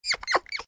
audio: Converted sound effects
AV_mouse_med.ogg